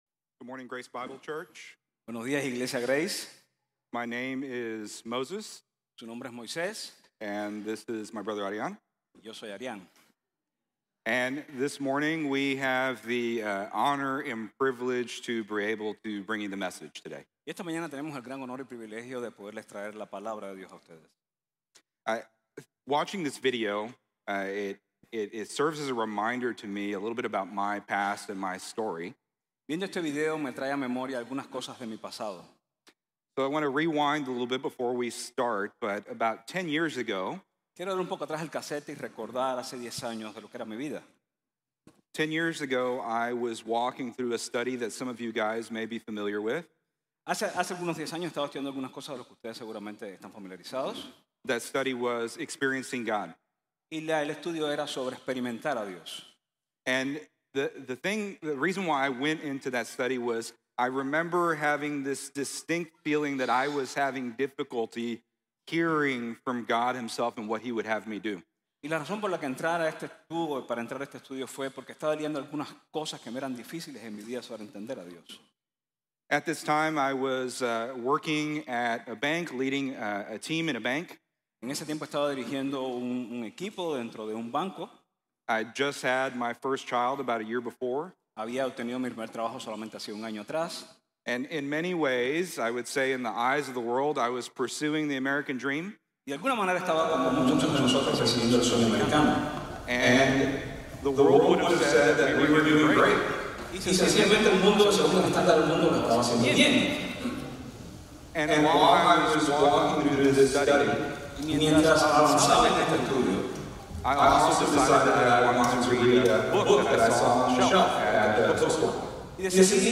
Venga el Reino: La promesa del cumplimiento | Sermon | Grace Bible Church